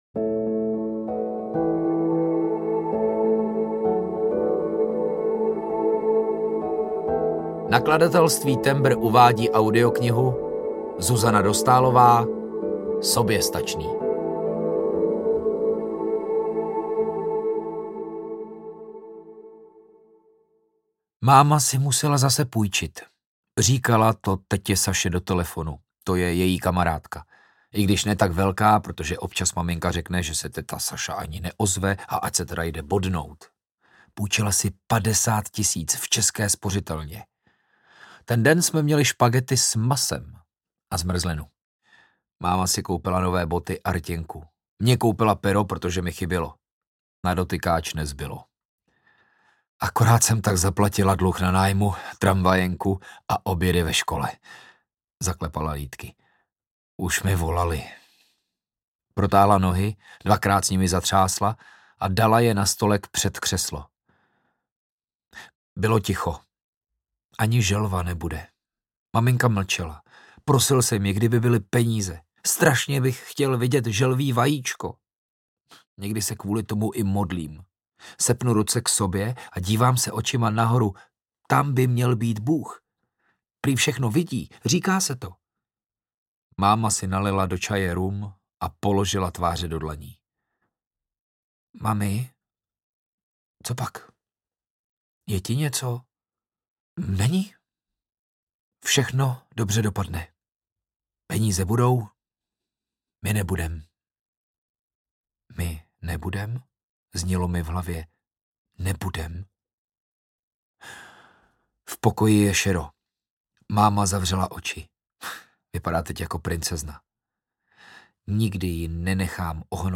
Soběstačný audiokniha
Ukázka z knihy
• InterpretLukáš Hejlík, Zuzana Kajnarová